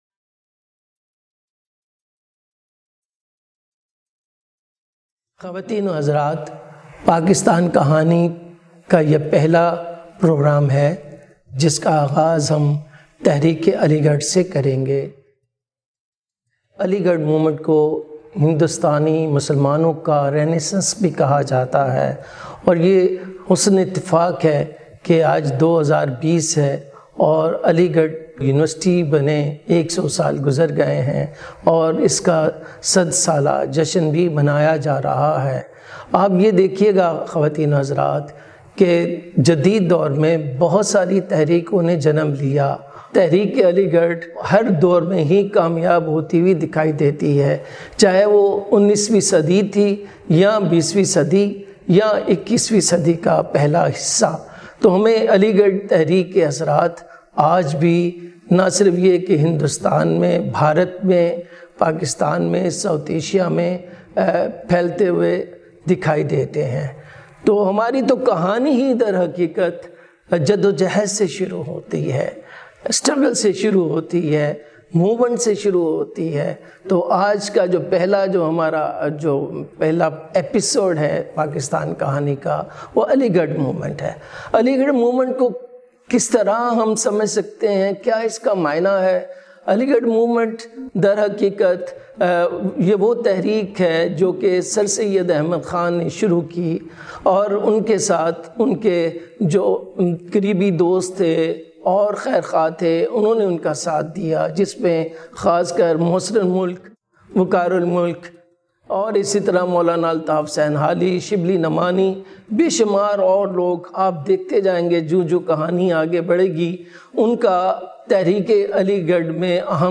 Lecture # 01 - Story of Pakistan